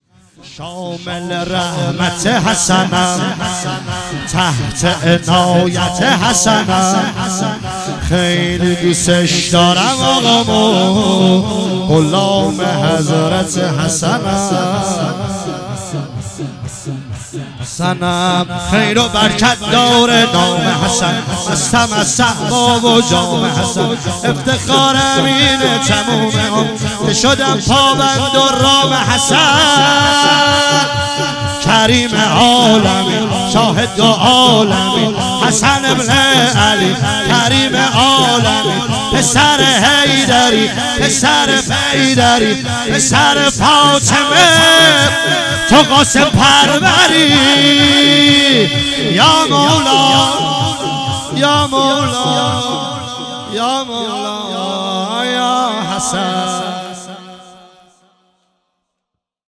شب 8 ماه رمضان 1398